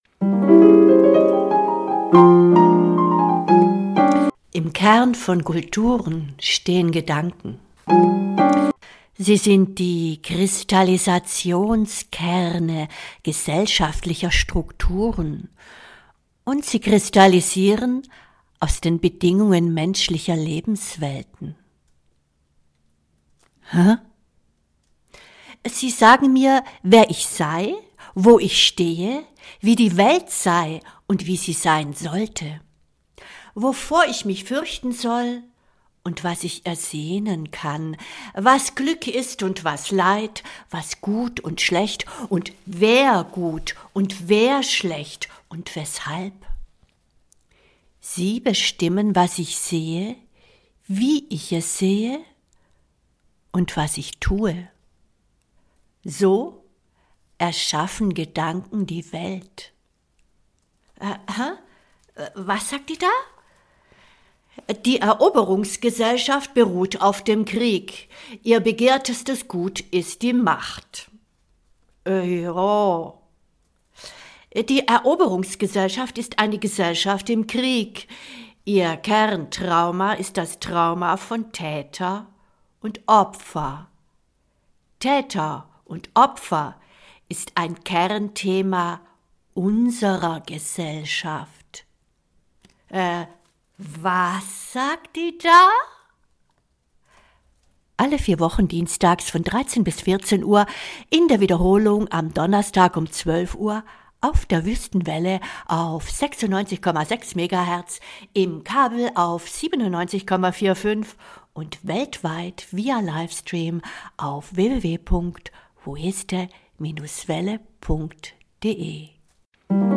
In dieser Sendung hört Ihr gleich mehrere Kapitel aus "Elviras ABC", garniert mit musikalischen Improvisationen von Klimper & Jaul.